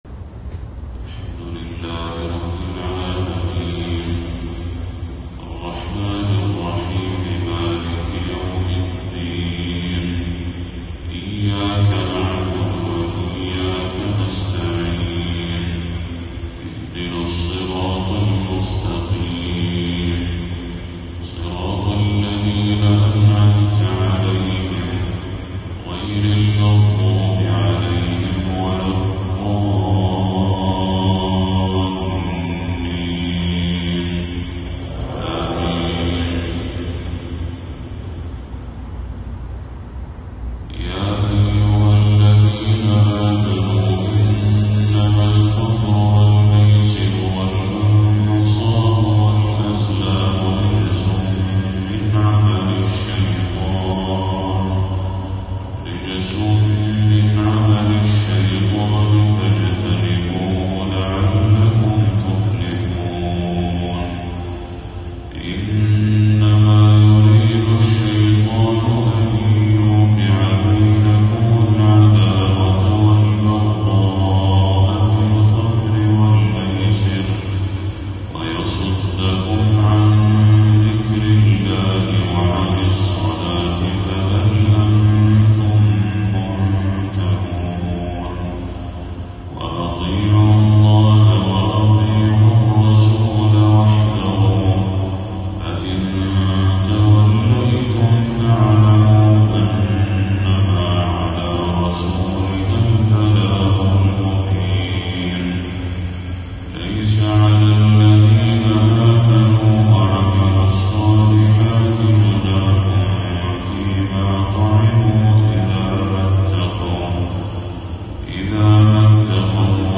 تلاوة حجازية من سورة المائدة للشيخ بدر التركي | فجر 14 صفر 1446هـ > 1446هـ > تلاوات الشيخ بدر التركي > المزيد - تلاوات الحرمين